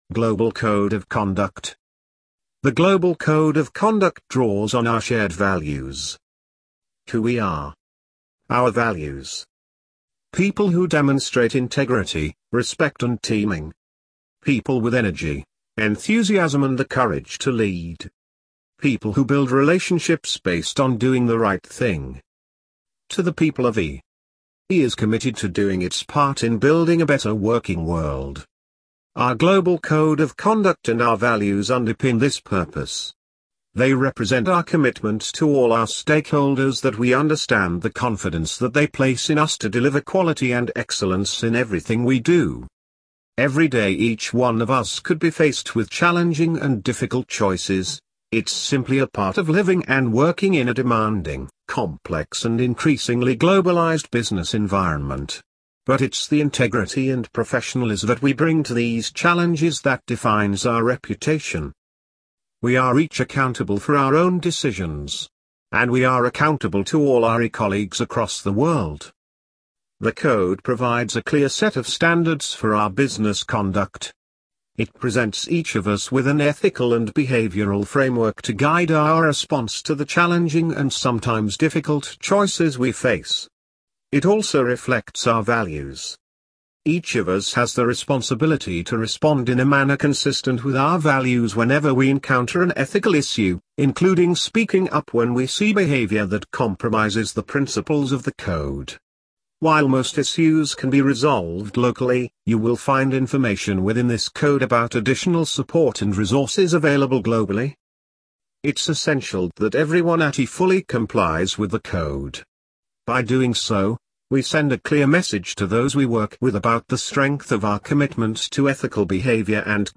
Global Code of Conduct - British Robot.mp3